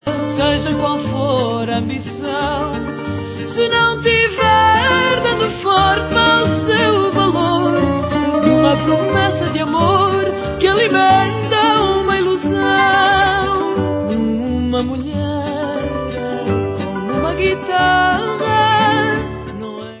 World/Fado